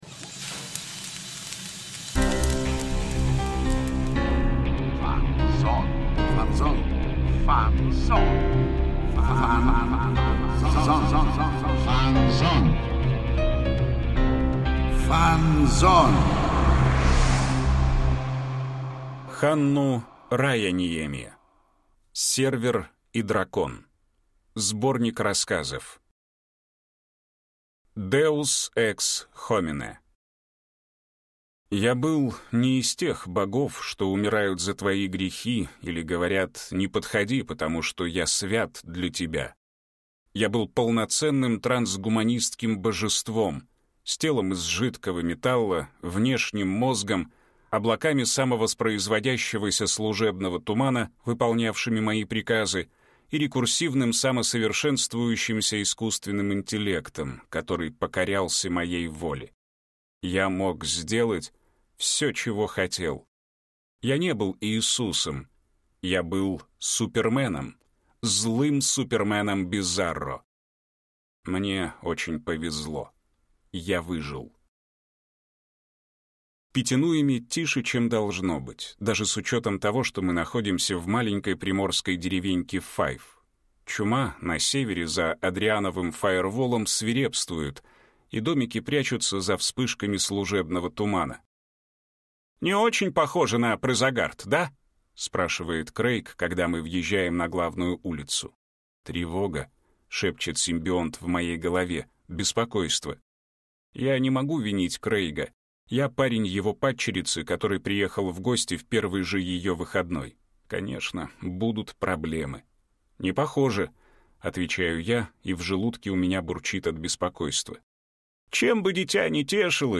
Аудиокнига Сервер и дракон | Библиотека аудиокниг